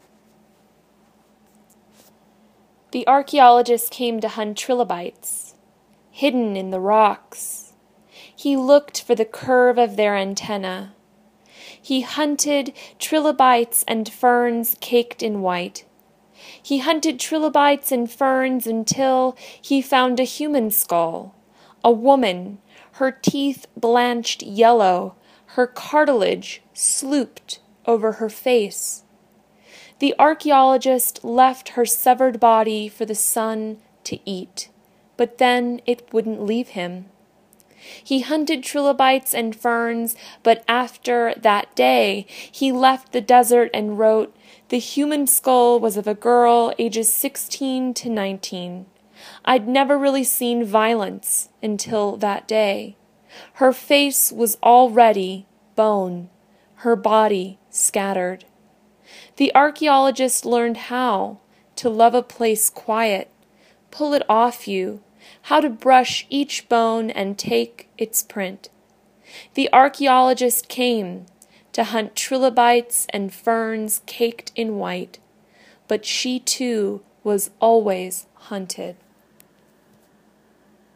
read this poem